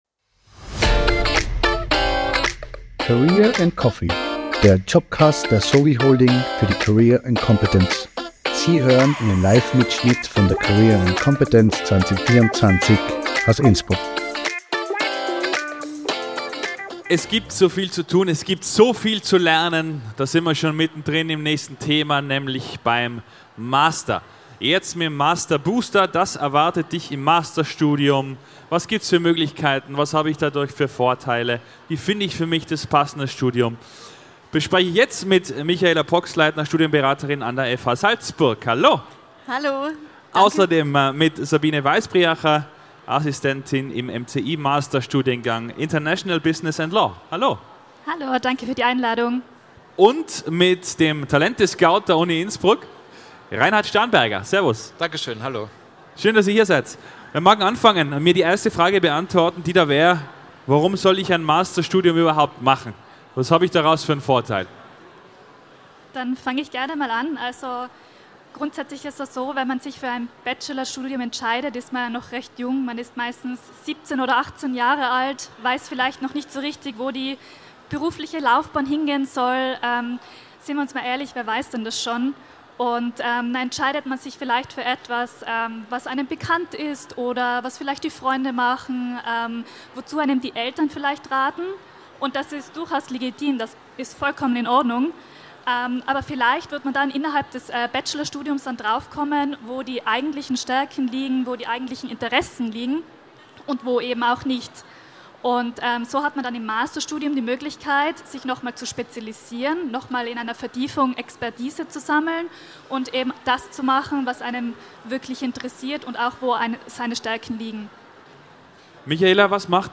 Livemitschnitt von der career & competence am 24. April 2024 im Congress Innsbruck.